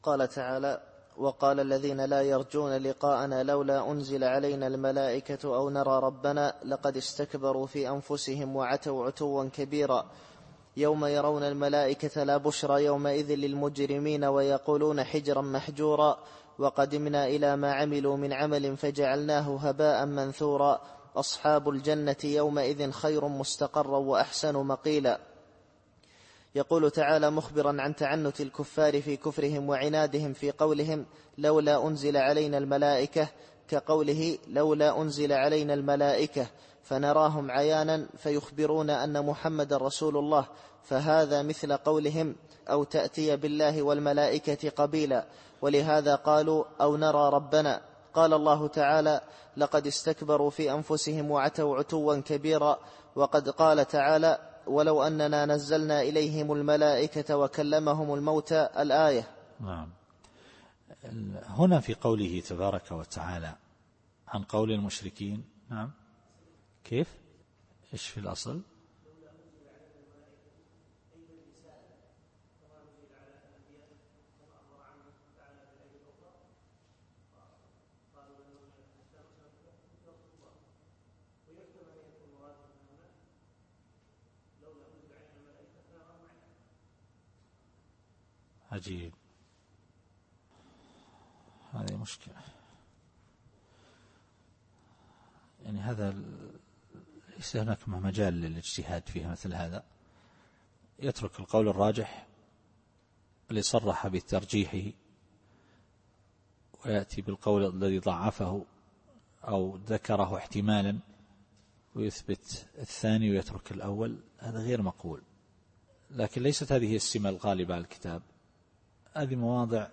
التفسير الصوتي [الفرقان / 21]